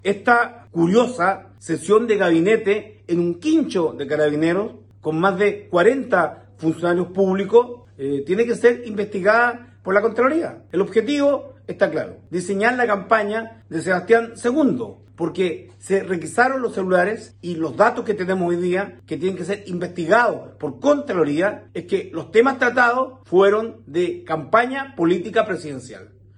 Frente a estos hechos, el senador Alejadro Navarro, dijo que llevará todos estos antecedentes a la Contraloría para que se investigue qué ocurrió al interior de esta unidad policial ante las posibles responsabilidades administrativas que podría conllevar este hecho.